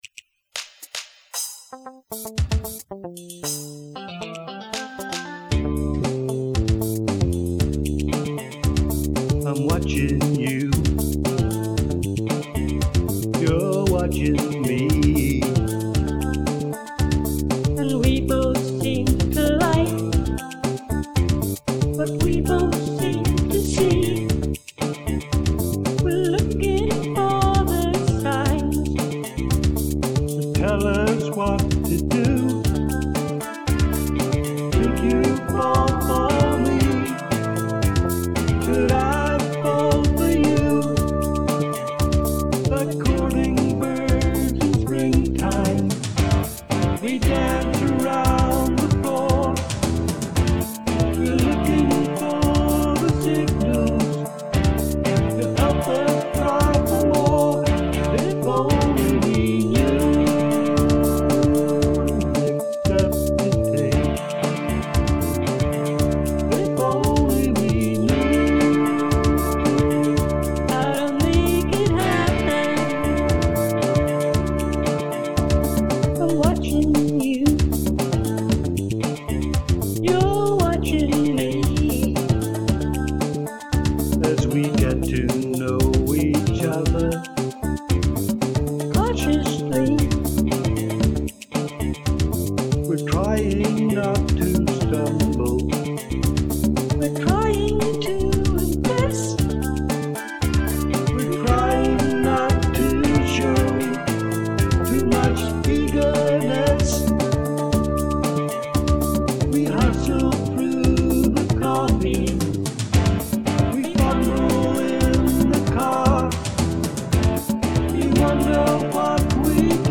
Duet "key"
Male Voice
Female Voice
Harmony